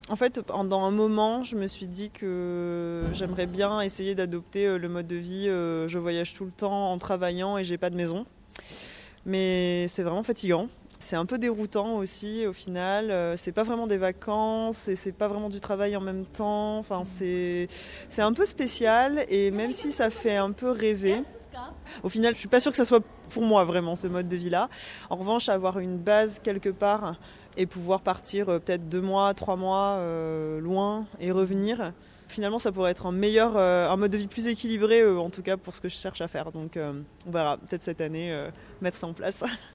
Elle m’a fait parvenir les éléments de l’interview (radio), que j’ai le plaisir de partager avec vous ici.